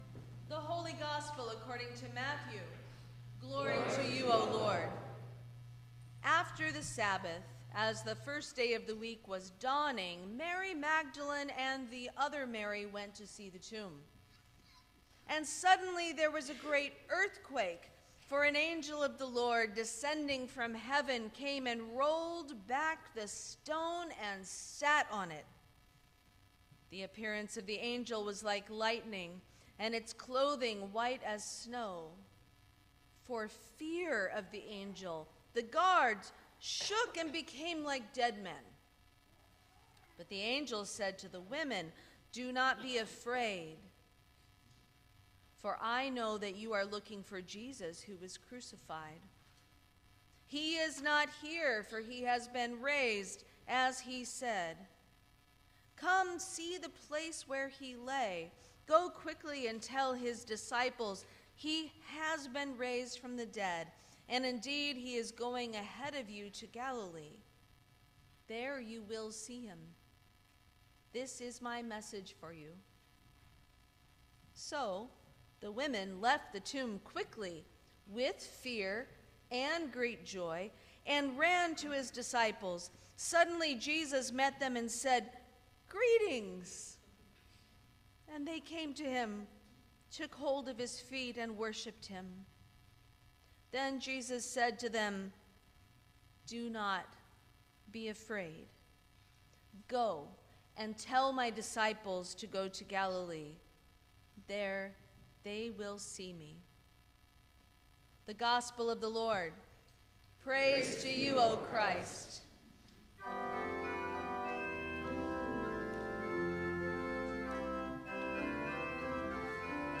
Sermon for Easter Day 2026